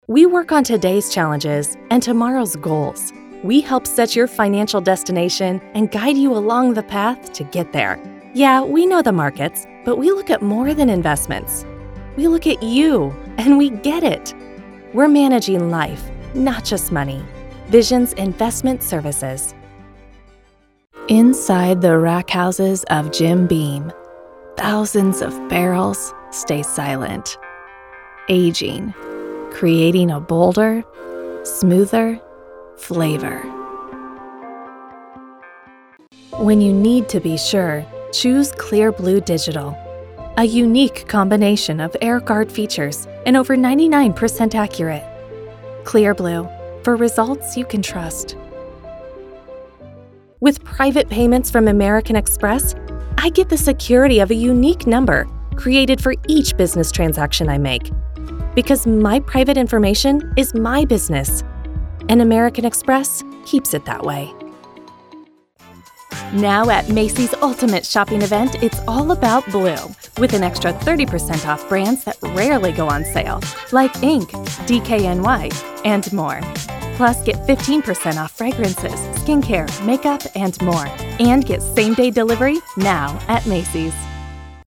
Voice Actor